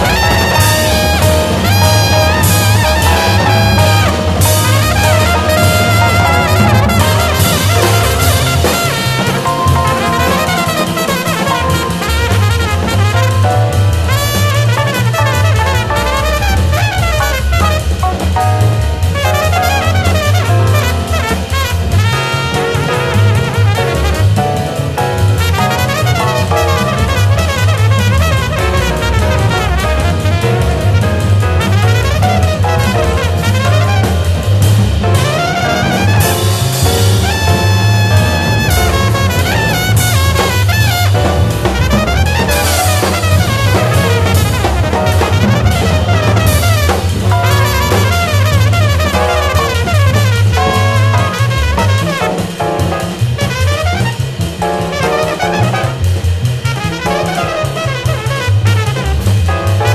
JAZZ / OTHER / FUSION / JAZZ ROCK
黄金期のコズミック・ジャズ・ロック金字塔！